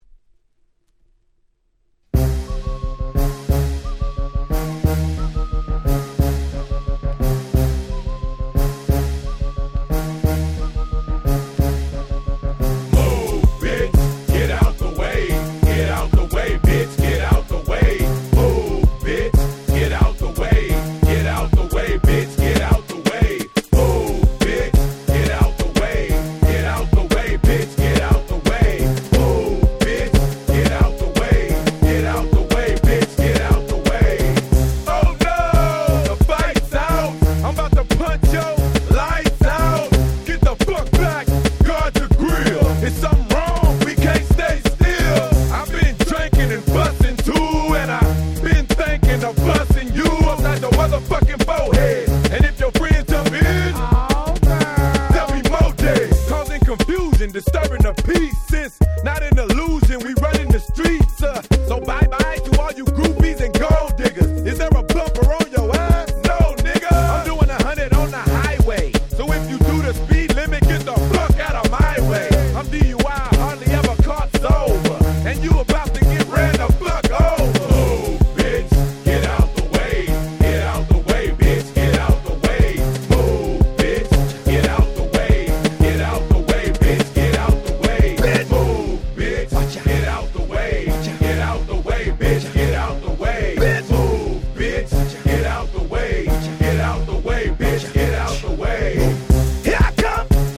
01' Super Hit Hip Hop !!